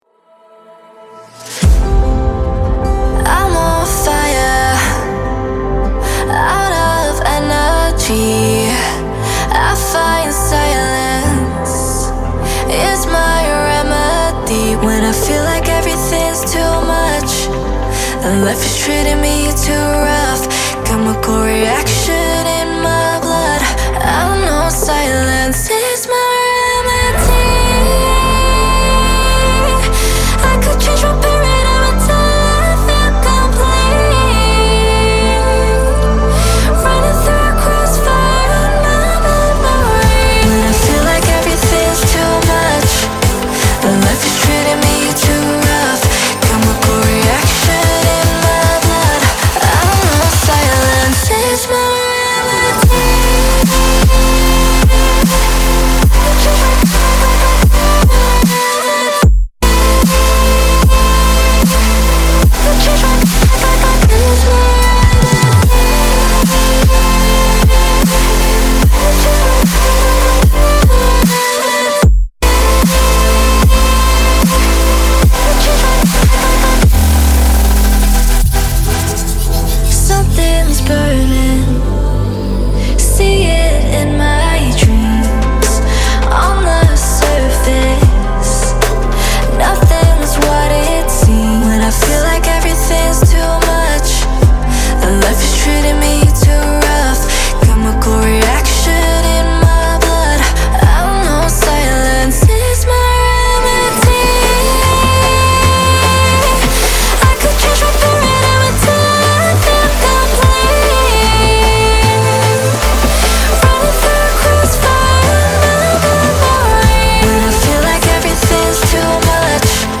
• Жанр: Trap